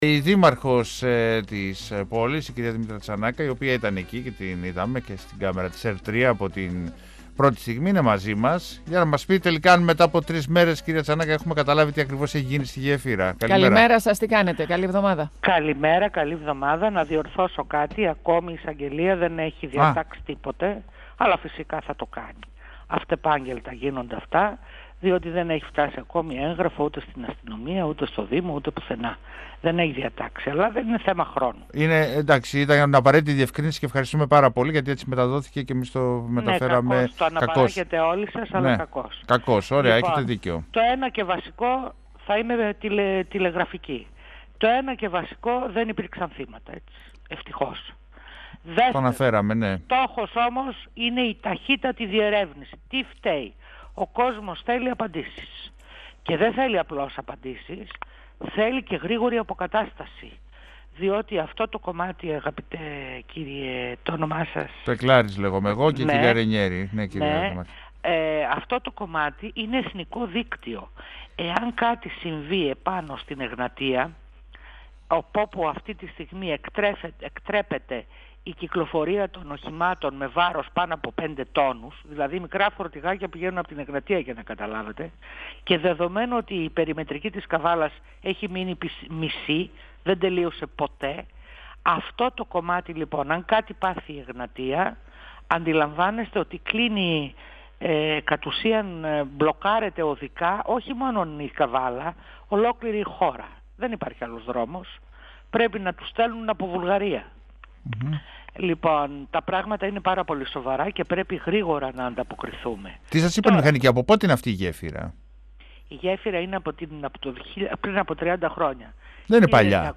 H δήμαρχος Καβάλας Δήμητρα Τζανάκα, στον 102FM του Ρ.Σ.Μ. της ΕΡΤ3